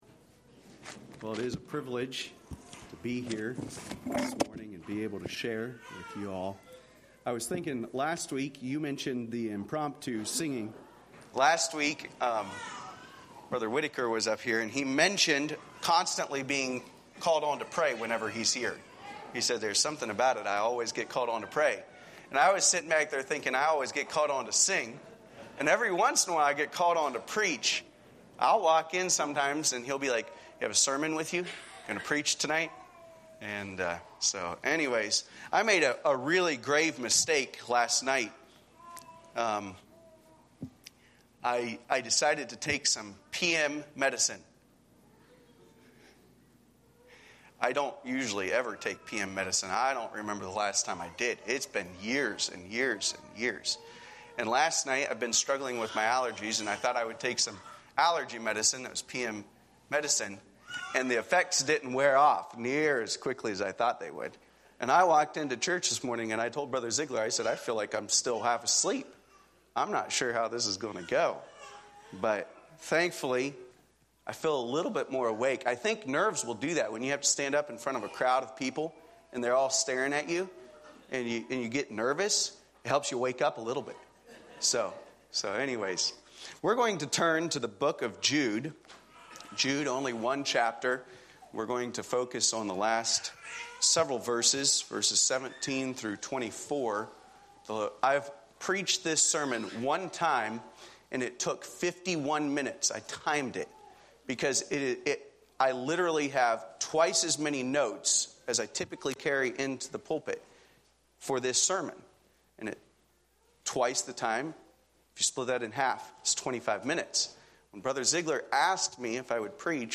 Save Audio A sermon